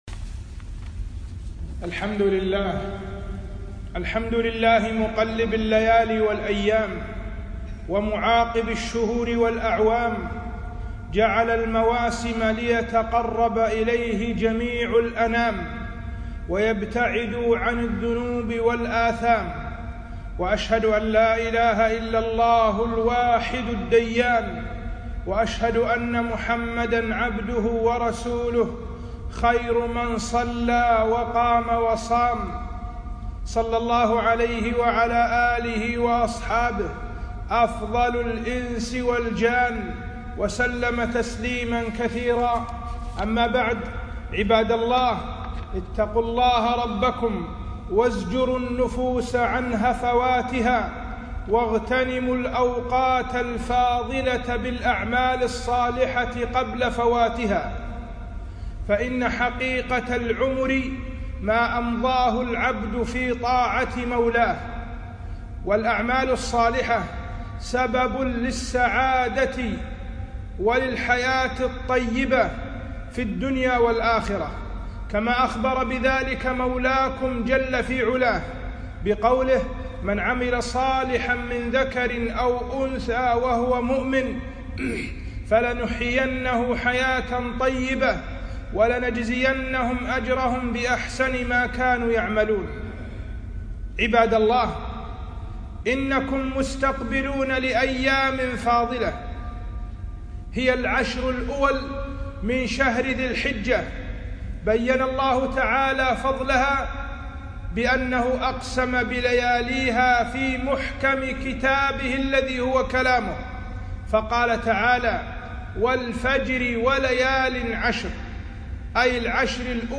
خطبة - فضل عشر ذي الحجة 1439هــ